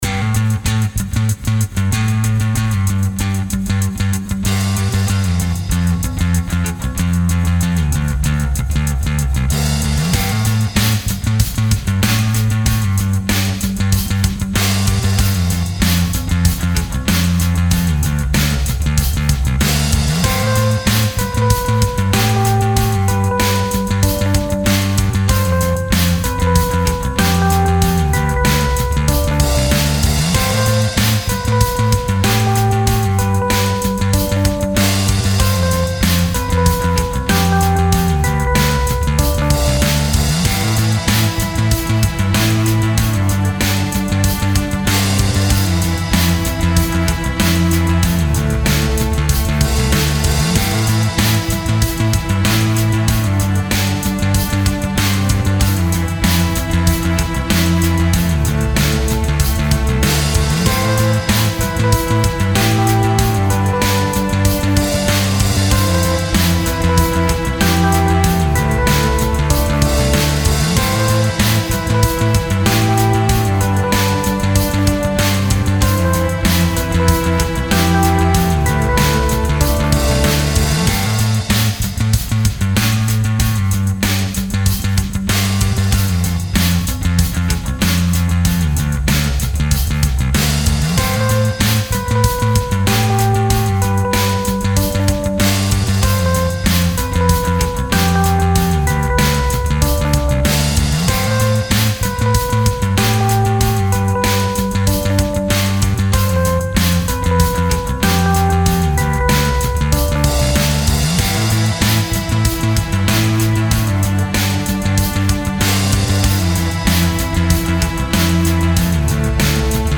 this song started with a bass guitar loop groove